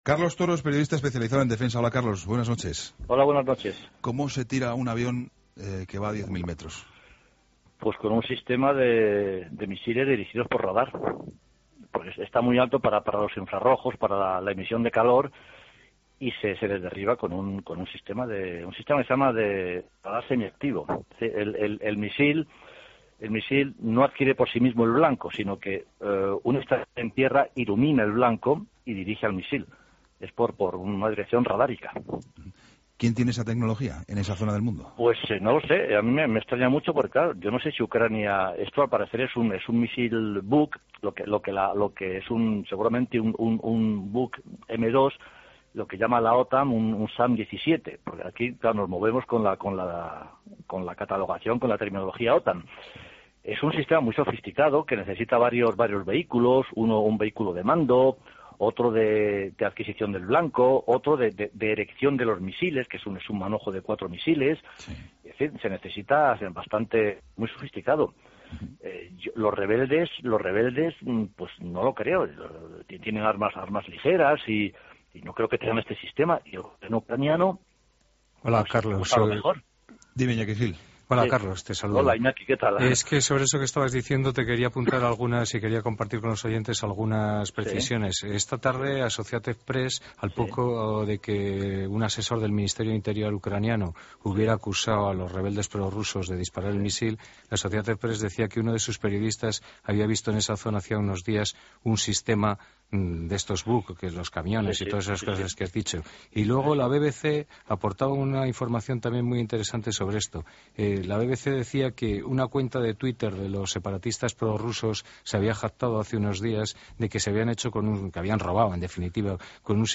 AUDIO: Periodista experto en Defensa
Entrevistas en La Linterna